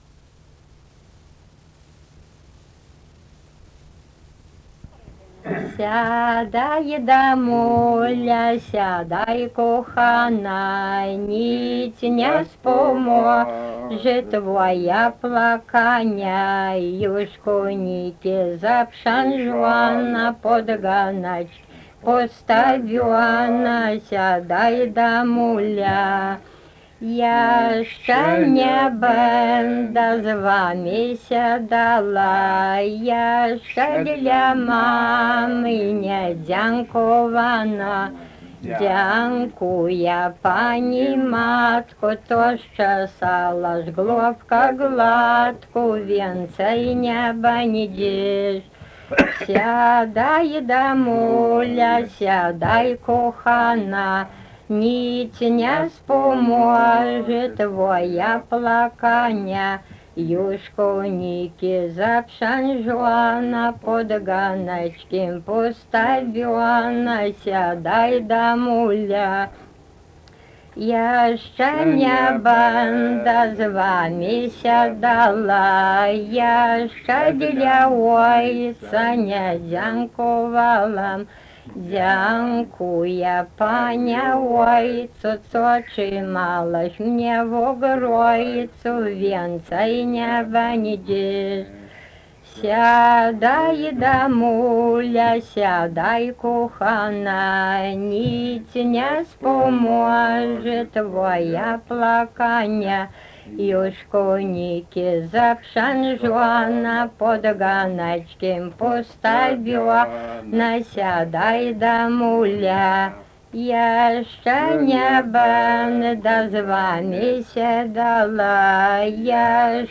daina
Krakės
vokalinis